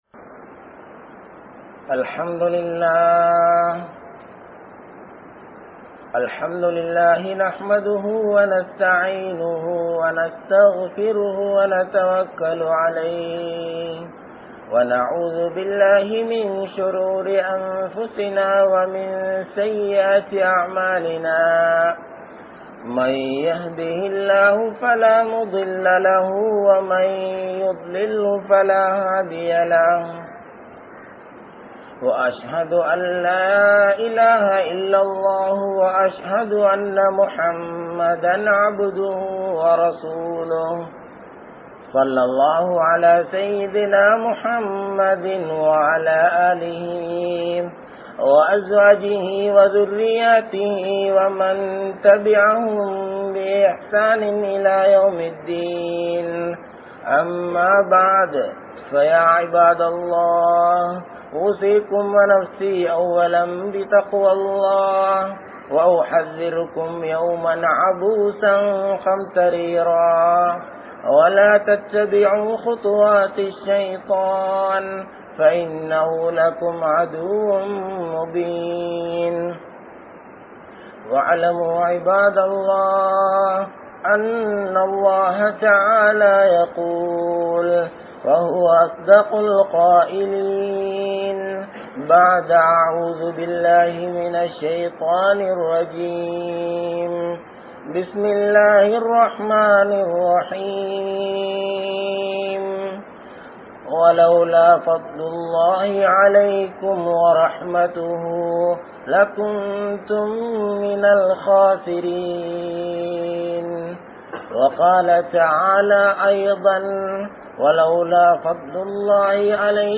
Allah`vai Eamaattra Ninaikkum Manitharhal (அல்லாஹ்வை ஏமாற்ற நினைக்கும் மனிதர்கள்) | Audio Bayans | All Ceylon Muslim Youth Community | Addalaichenai